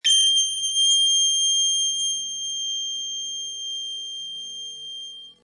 Download High Pitch sound effect for free.
High Pitch